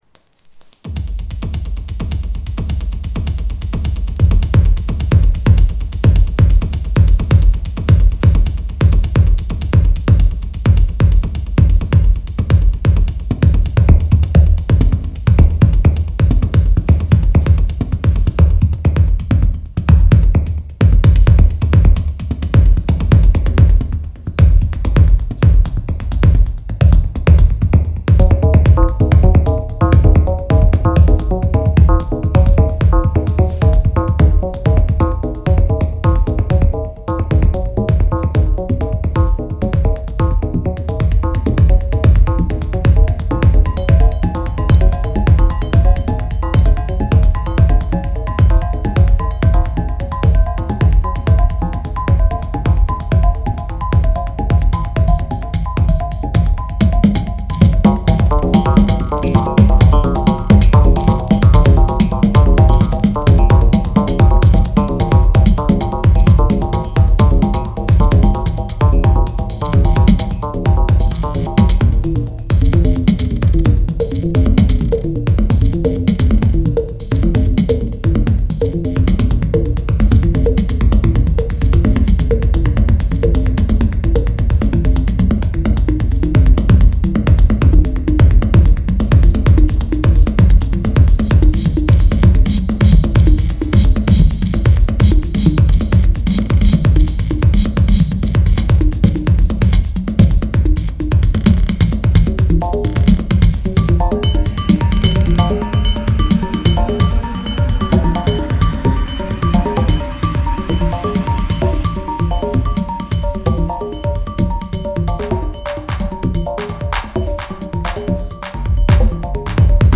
2023, interactive digital sound installation, 400 cm x 16 cm x 3 cm. 16 laser sensors, 16 RGB LEDs, 4 ESP32, router, audio interface, computer.
The techno sound wall is similar to a sequencer – playing back and processing data to produce music – in which, by triggering the sensors, limited random processes are set in motion. The 16 sensors contain rhythmic instruments, various drum and sound characteristics from club and pop music, with fixed and at the same time random behaviour patterns that can be triggered, varied and combined intuitively by hand.